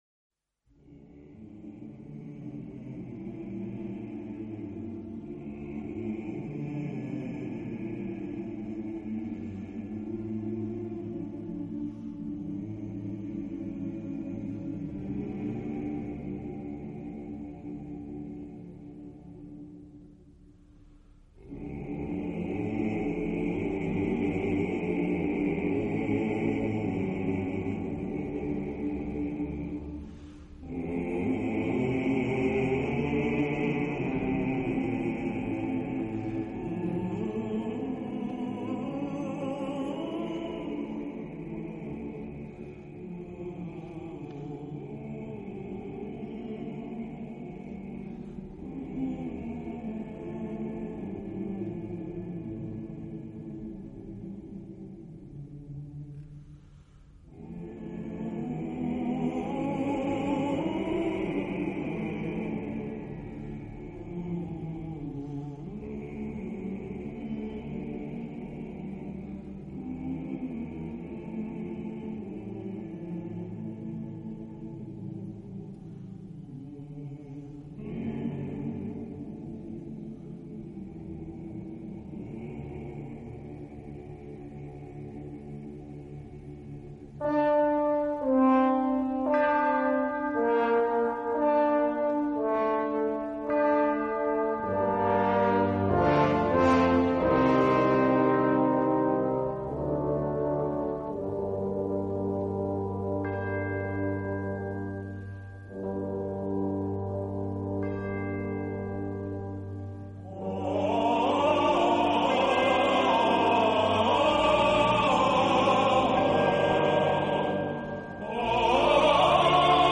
演奏以轻音乐和舞曲为主。